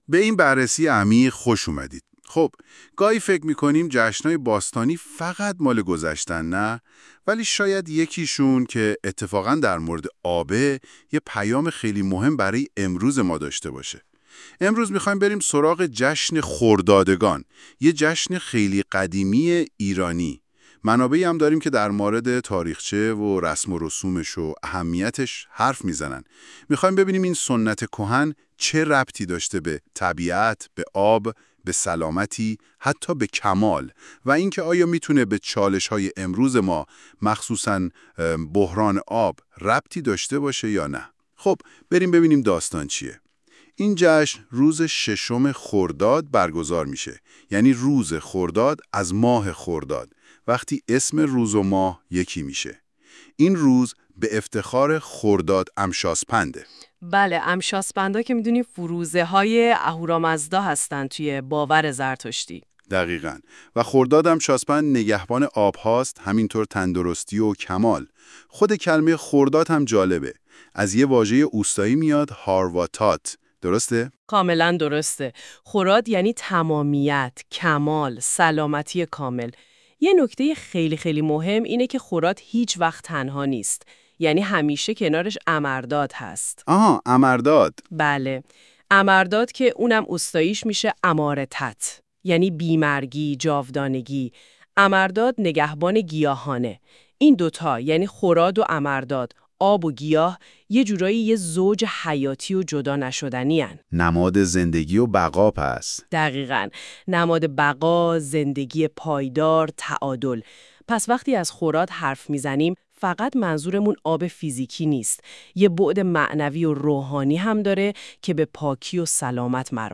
این فایل صوتی با استفاده از هوش مصنوعی تولید شده است تا تجربه‌ای بهتر و کاربرپسندتر برای شما فراهم شود.